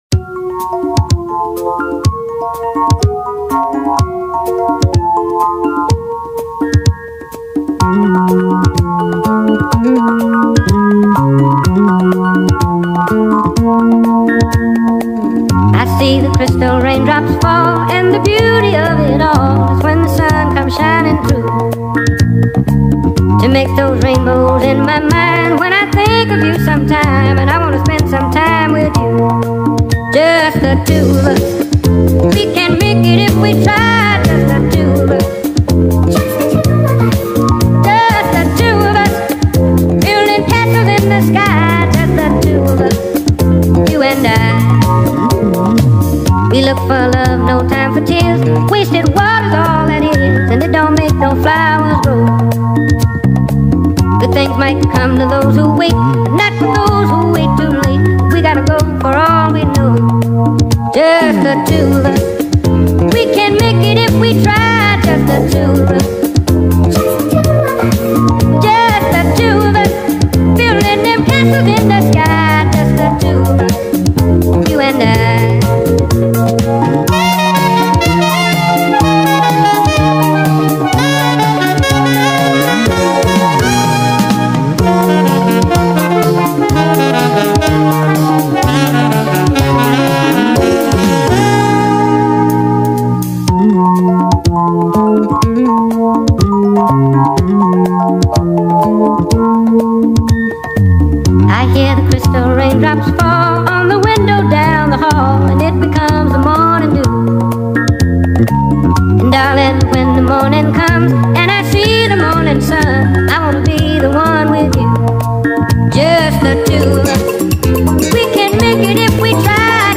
با ریتمی سریع شده
عاشقانه